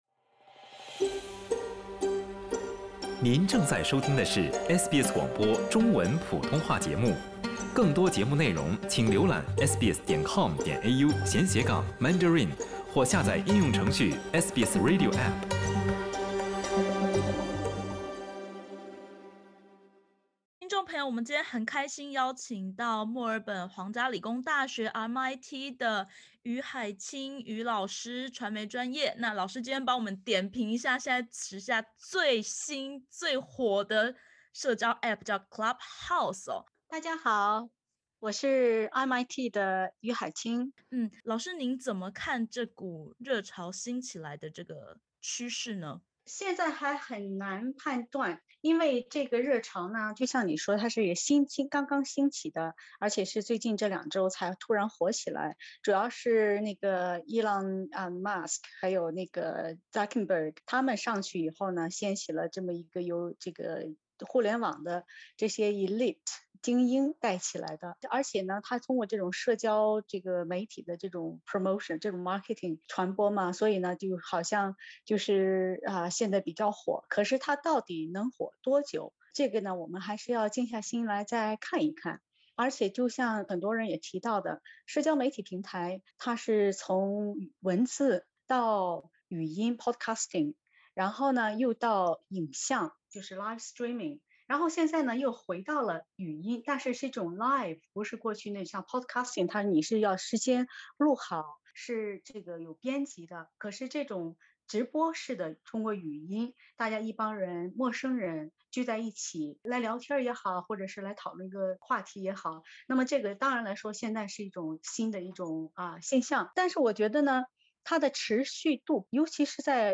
当前最火的社交应用Clubhouse，是采私人邀请制的语音社交平台，这股新兴热潮如何解析？（点击首图收听采访音频）